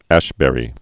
(ăshbĕrē, -bə-rē), John 1927–2017.